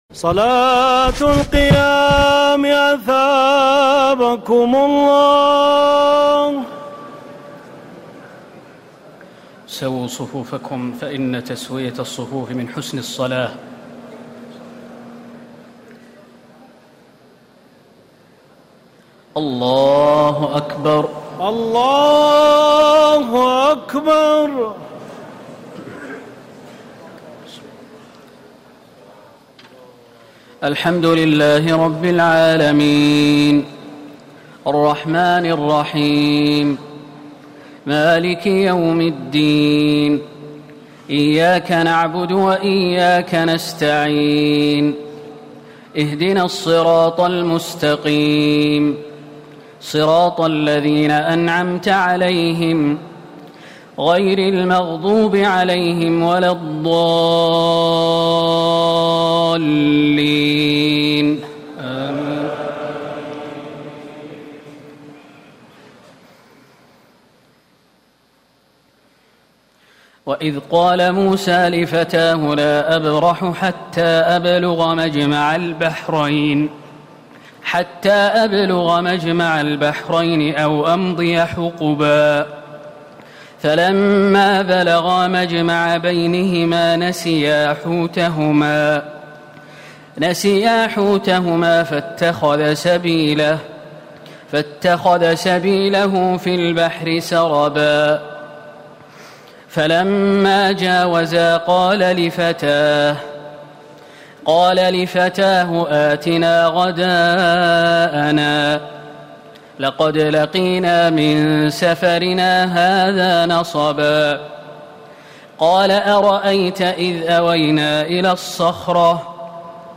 تراويح الليلة الخامسة عشر رمضان 1439هـ من سورتي الكهف (60-110) و مريم كاملة Taraweeh 15 st night Ramadan 1439H from Surah Al-Kahf and Maryam > تراويح الحرم النبوي عام 1439 🕌 > التراويح - تلاوات الحرمين